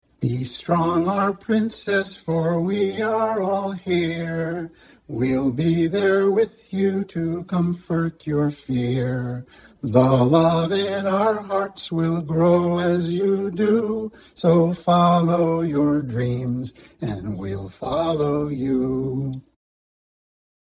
This entry was posted in A to Z Blog Challenge 2025, Original Song, Original Stories, Writing and tagged , , .
Project-Rowans-Support-chorusD-.mp3